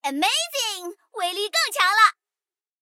M3格兰特强化语音.OGG